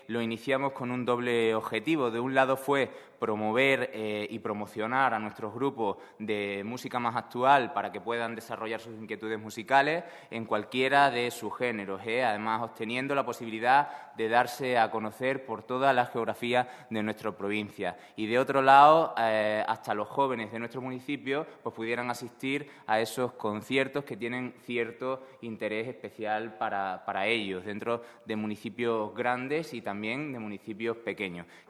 CORTES DE VOZ